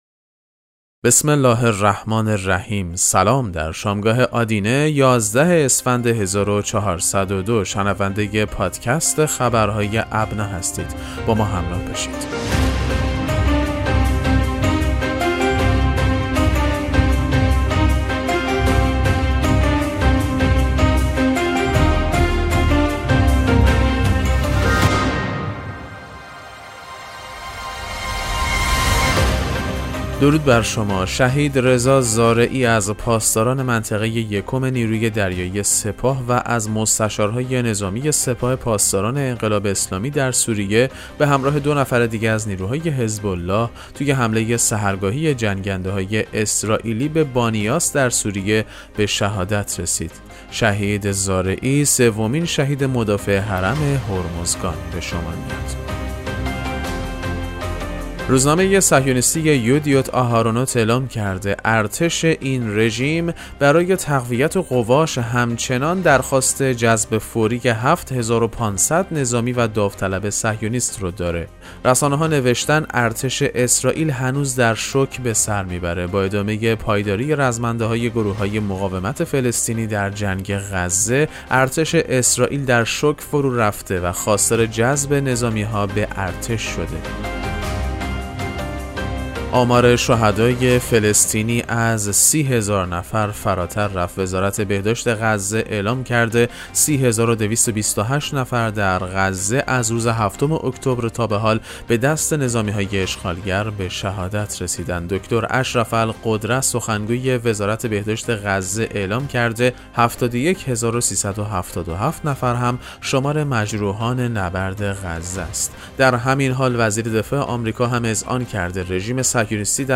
پادکست مهم‌ترین اخبار ابنا فارسی ــ 11 اسفند 1402